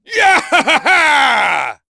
Chase-Vox_Happy4.wav